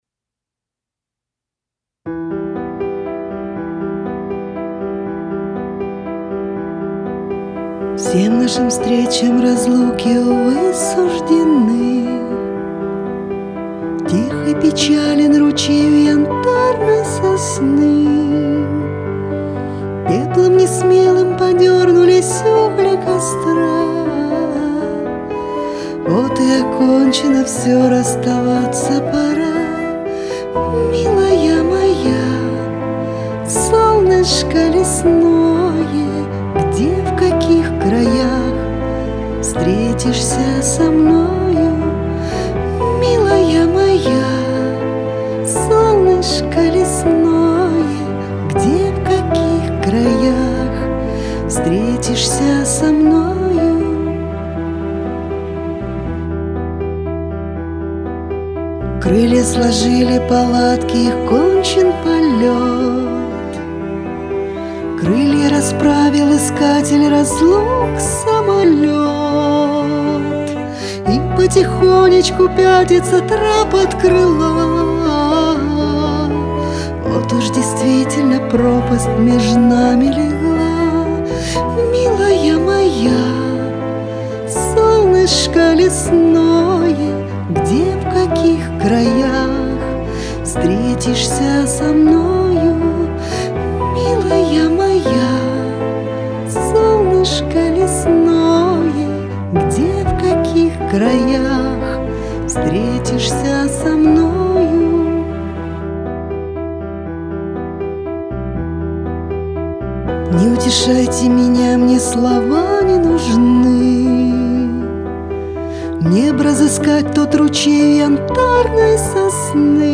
под аккомпанемент пианино